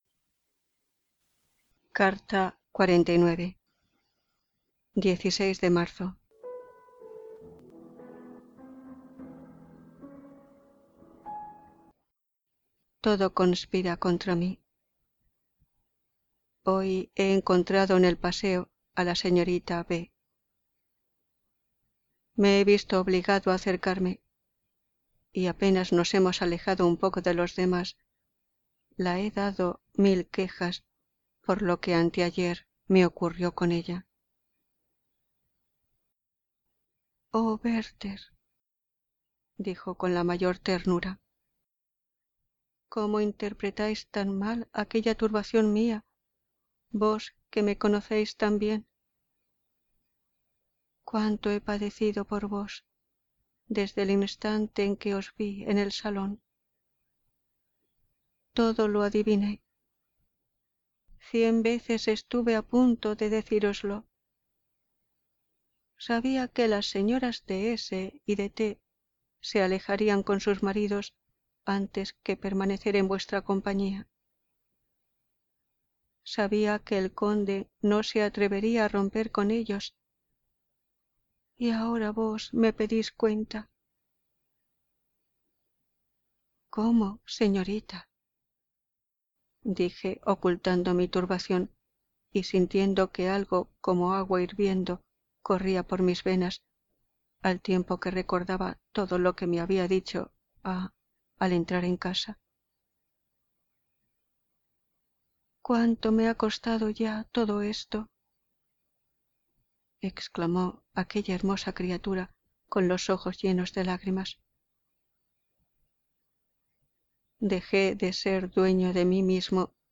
Música: Brahms - Three Violín Sonatas - Sonata N 3 - Op. 108